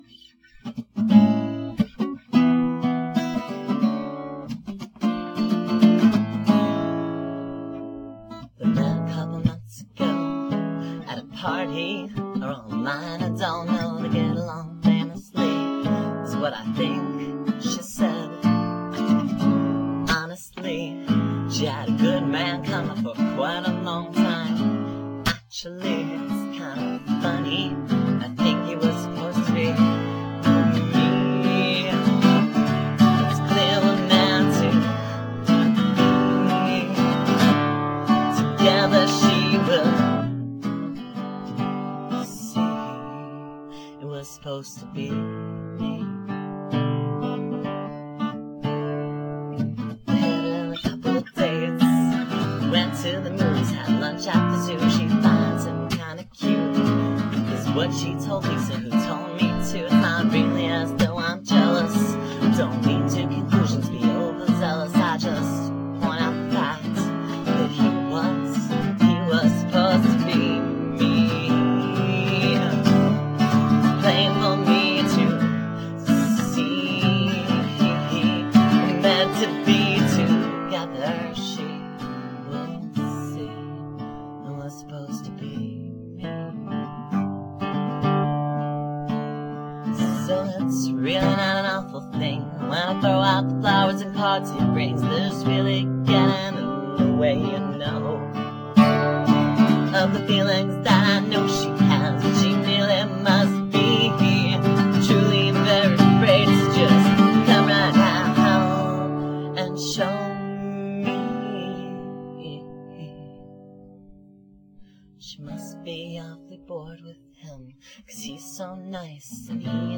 “Supposed To Be” is a narrative song, a peculiar blend of “Every Breath You Take” and “Old Apartment” that would seem alien in just about any set of mine.
It has a lot of chords, and a lot of words.
The lack of rehearsal makes its placement as the final tune a brave one, but apparently I had nothing to worry about – “Supposed To Be” came out perfect in a single try – so much so that I’ve hardly ever played it since.